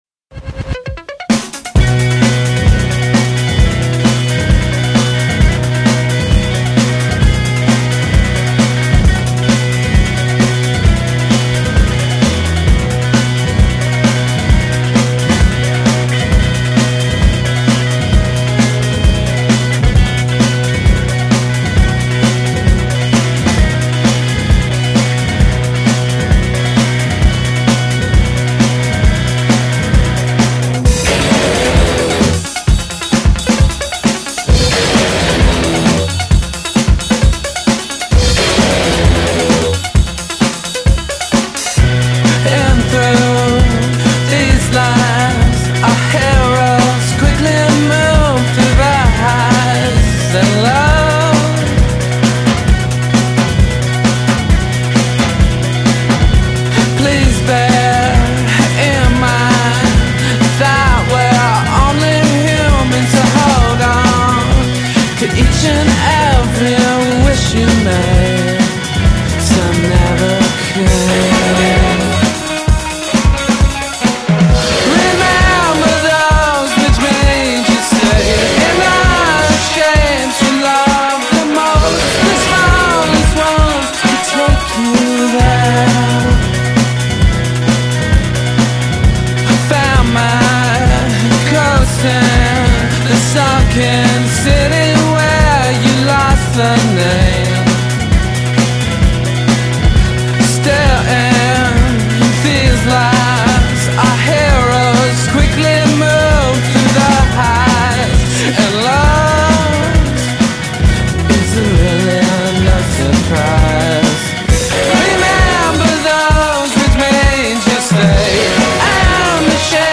euphoric dance rock